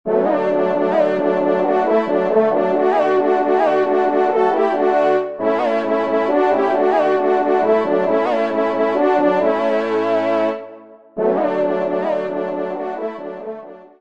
Genre : Musique Religieuse pour  Quatre Trompes ou Cors
ENSEMBLE